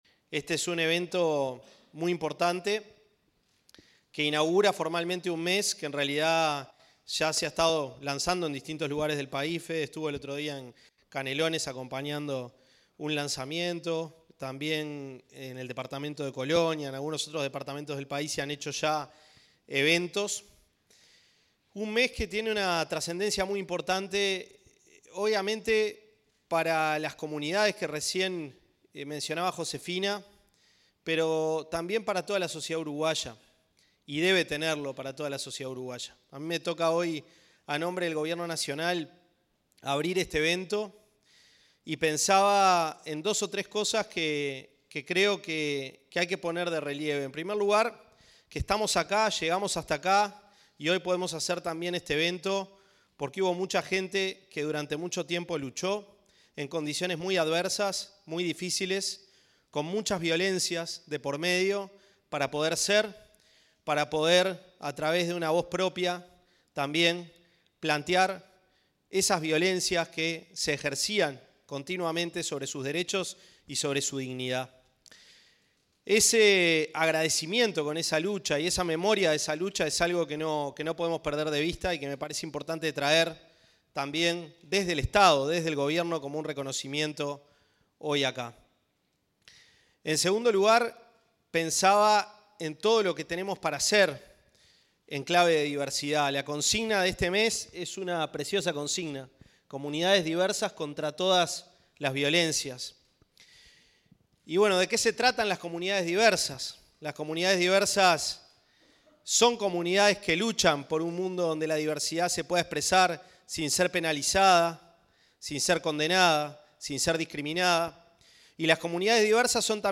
Palabras de autoridades en el lanzamiento del Mes de la Diversidad
Palabras de autoridades en el lanzamiento del Mes de la Diversidad 05/09/2025 Compartir Facebook X Copiar enlace WhatsApp LinkedIn El ministro de Desarrollo Social, Gonzalo Civila; el director nacional de Desarrollo Social, Nicolás Lasa, y la titular de la Secretaría de Derechos Humanos de la Presidencia de la República, Colette Spinetti, participaron, en la Torre Ejecutiva, en la apertura del lanzamiento del Mes de la Diversidad.